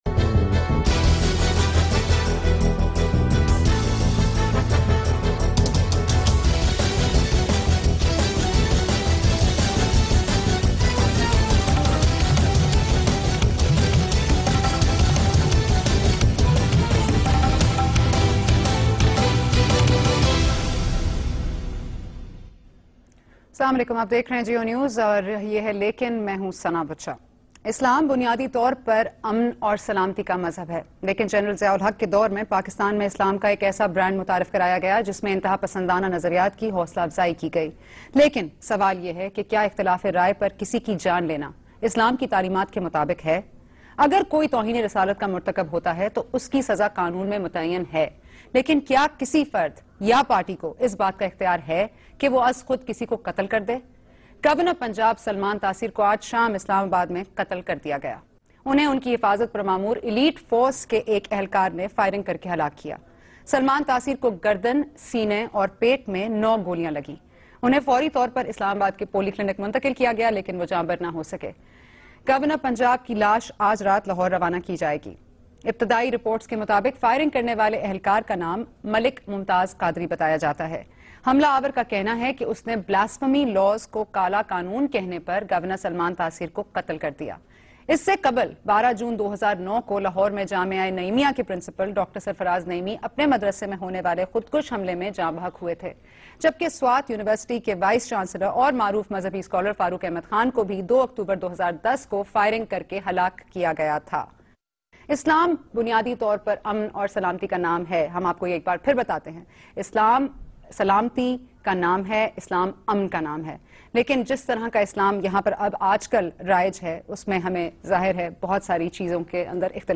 In this program Saleem Safi discusses Democracy in the Muslim World with Javed Ahmad Ghamidi